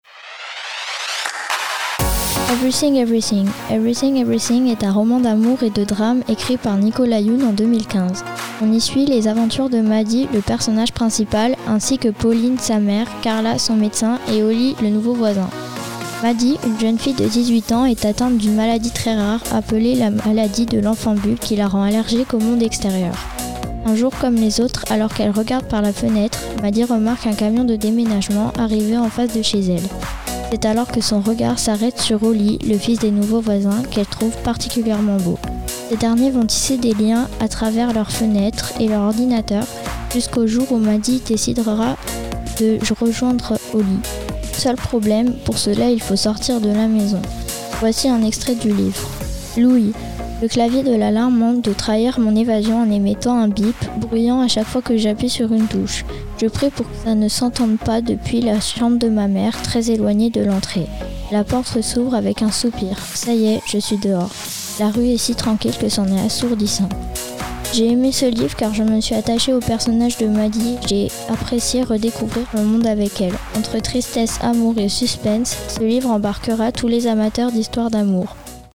Chronique sur le roman